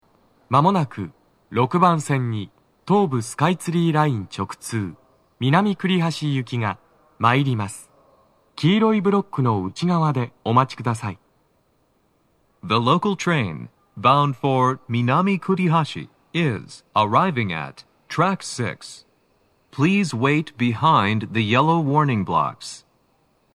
男声
接近放送6